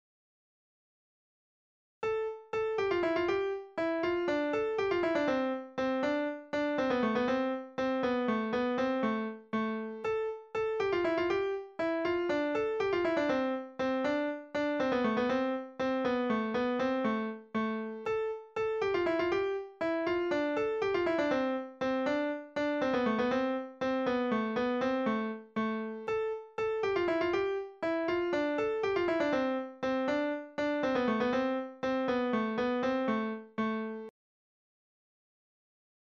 Click to here two variations of the Yablochko tune (mp3 files): Yablochko 1 and Yablochko 2
Yablochko, a basic tune, was used as musical accompaniment for the chastushki, a type of Russian urban folklore ditties characterized by absurd logic and almost overt sexuality or political mockery.